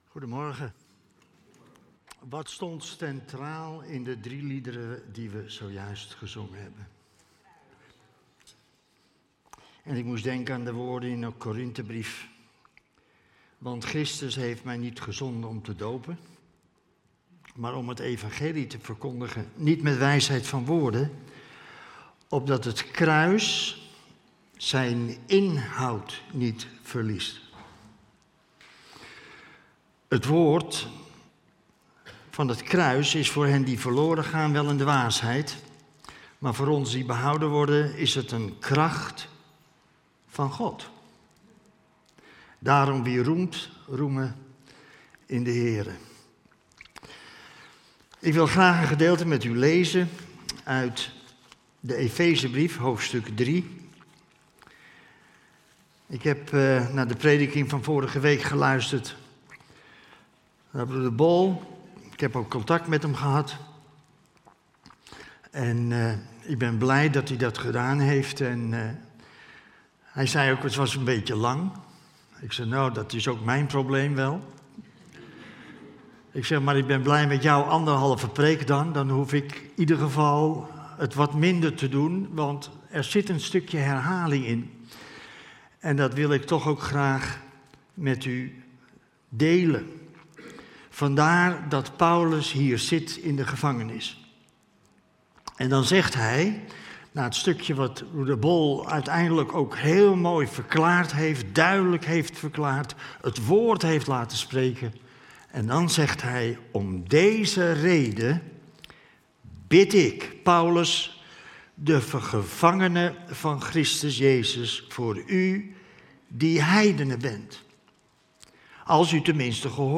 Ben jij benieuwd? luister dan snel deze aflevering of kijk de preek terug op ons YouTube kanaal.